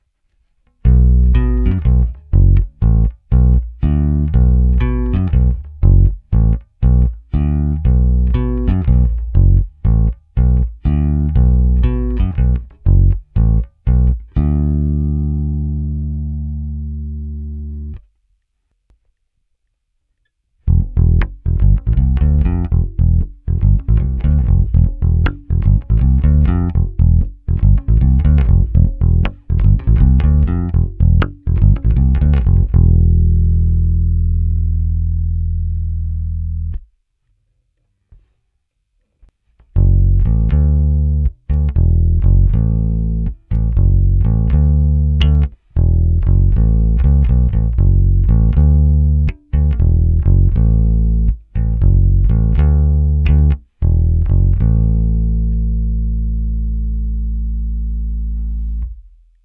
Následující nahrávky jsou nahrávány přímo do zvukovky, bez jakýchkoli úprav (mimo normalizace).
Nahráno na skládačku precision, struny La Bella Deep Talkin Bass flatwounds, tónová clona otevřená.